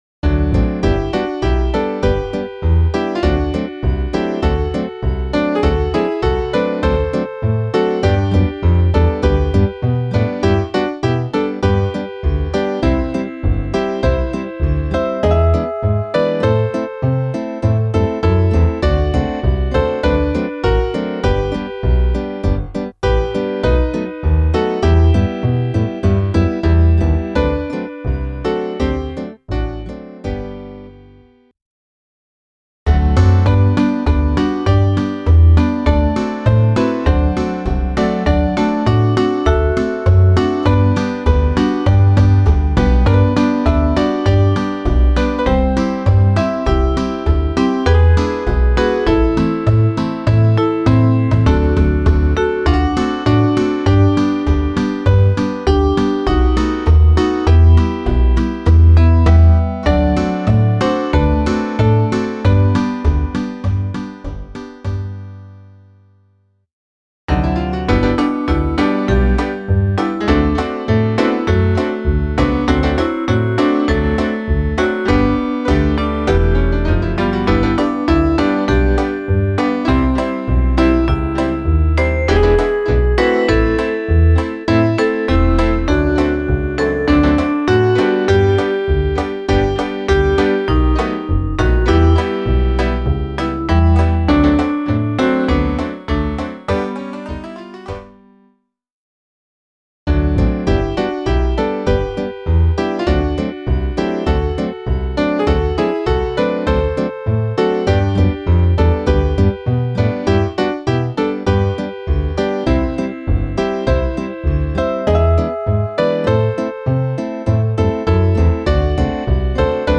Klaviersatz
Variationen